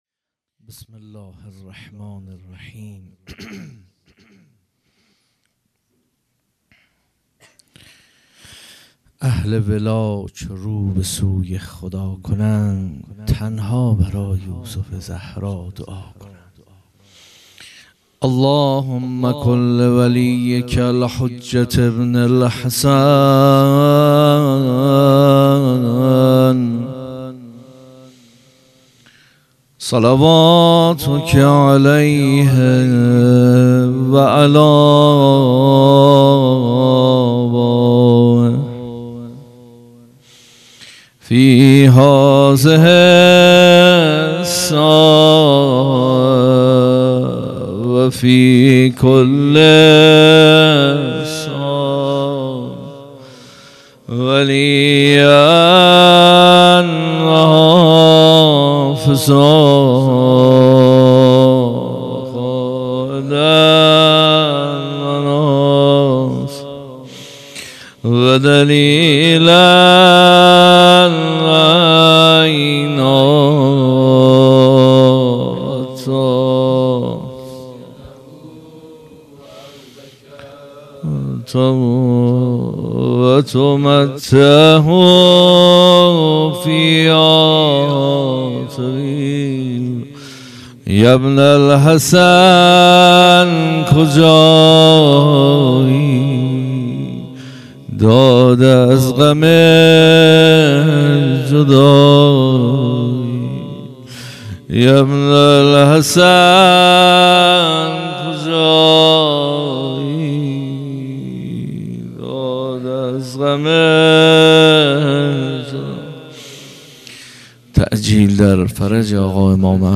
هیئت مکتب الزهرا(س)دارالعباده یزد - موضوع | امام کیست ؟ ۲ سخنران